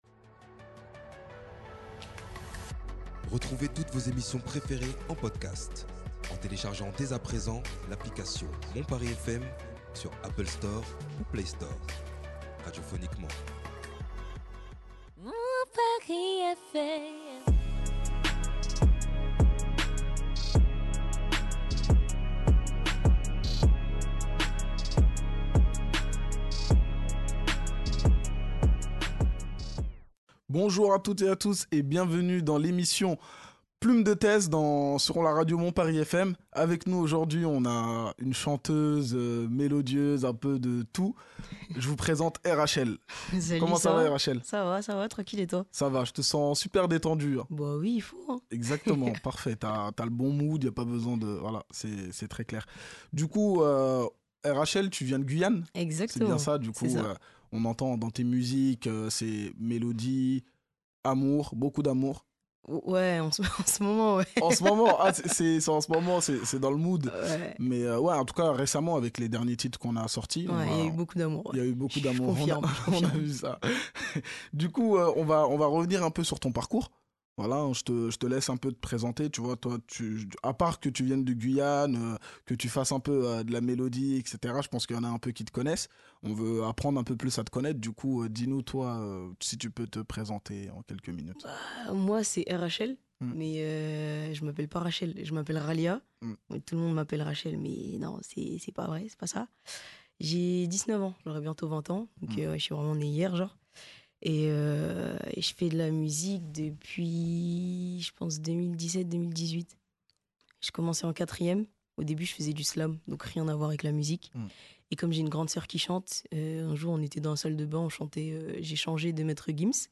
Ensemble ils retracent son parcours, ses succès et ses perspectives futurs, sans oublier la partie live.